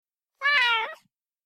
Cat.mp3